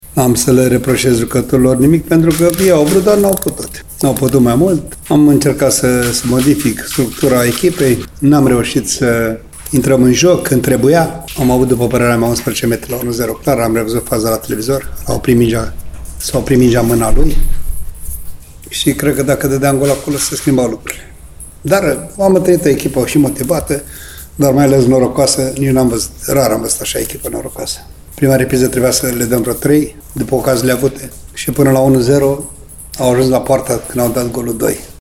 Antrenorul bănățenilor, Ionuț Popa, spune că nu are ce să le reproșeze jucătorilor săi.